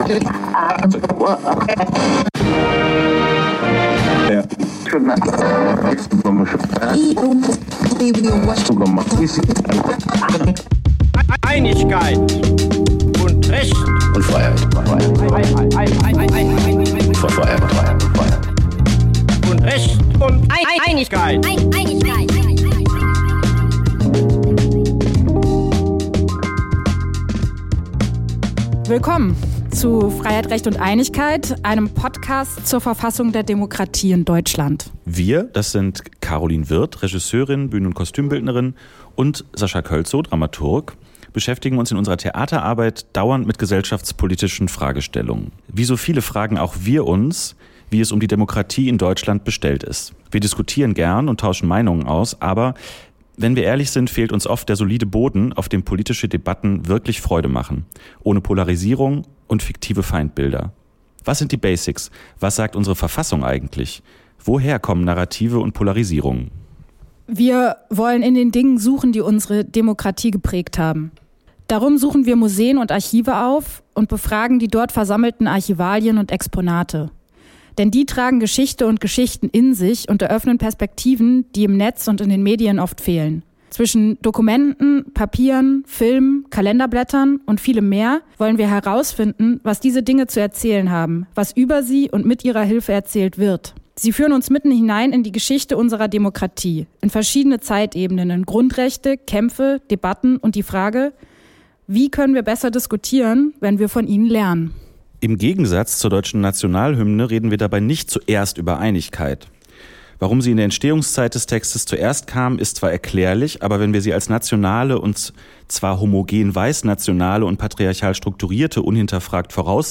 Podcast-Folge 1: Solidarität und Kämpfe für Freiheit: zu Besuch im Archiv im Haus der Geschichte des Ruhrgebiets